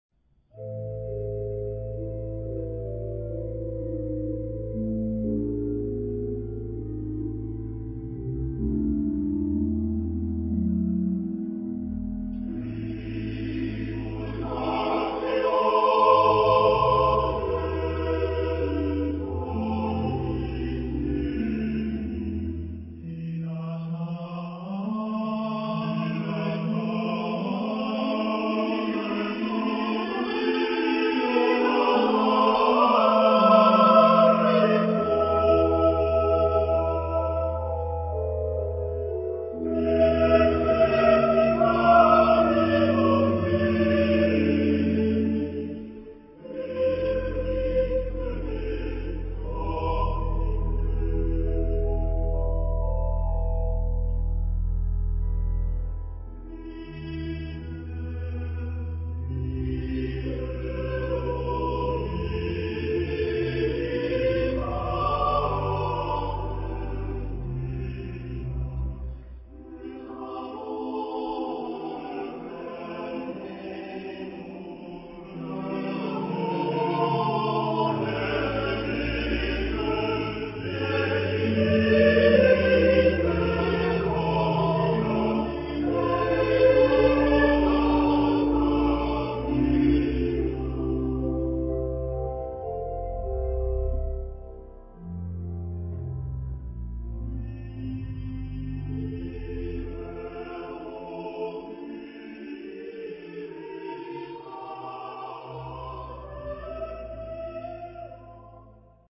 Genre-Style-Forme : Sacré ; Romantique ; Hymne (sacré)
Type de choeur : SATB  (4 voix mixtes )
Instrumentation : Basse continue
Instruments : Orgue (1) ; Violoncelle (1)
interprété par Kammerchor Stuttgart dirigé par Frieder Bernius